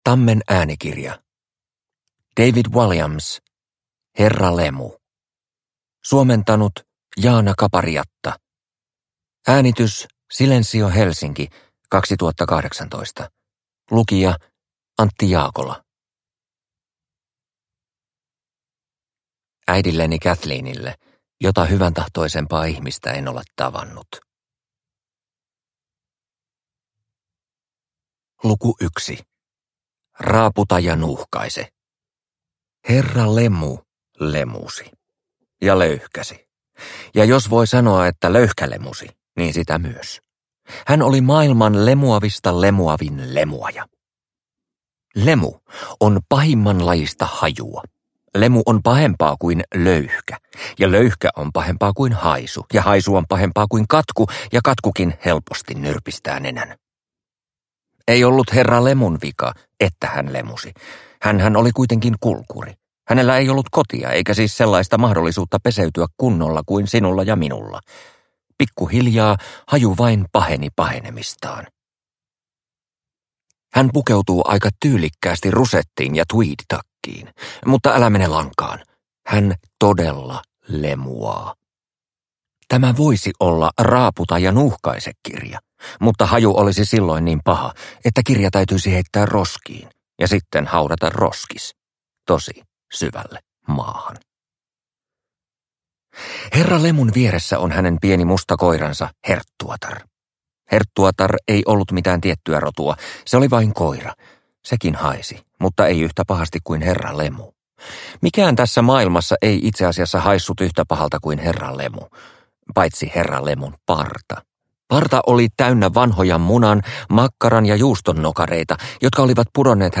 Herra Lemu – Ljudbok – Laddas ner